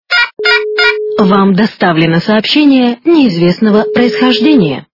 - звуки для СМС
При прослушивании Звонок для СМС - Вам доставлено сообщение неизвестного происхождения качество понижено и присутствуют гудки.